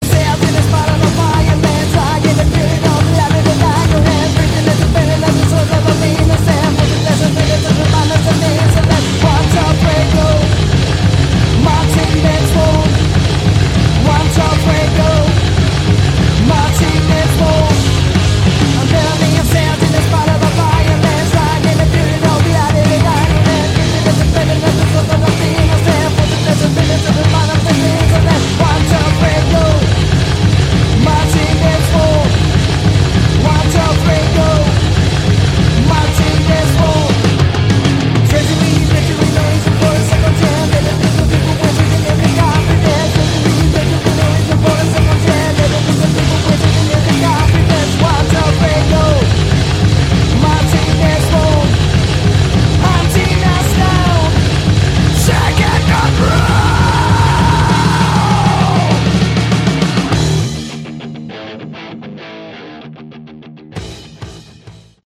Category: Modern Hard Rock/Punk